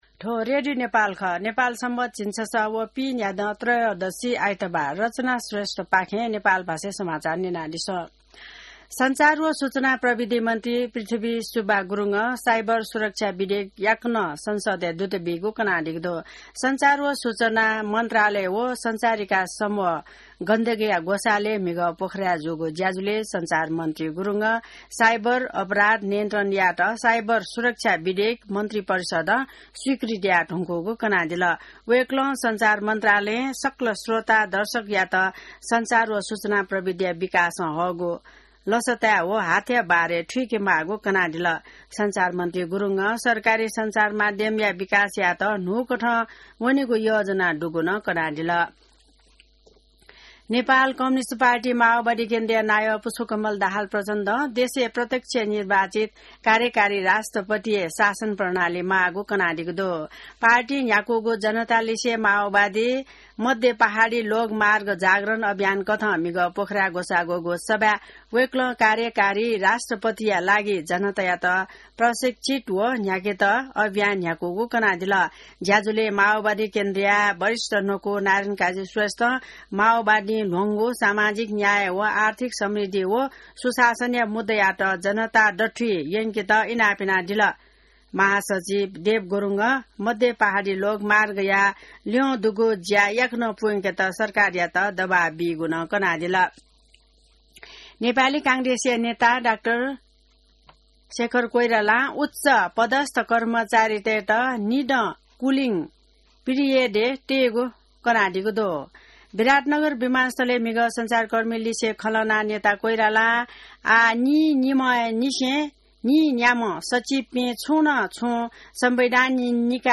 नेपाल भाषामा समाचार : ११ जेठ , २०८२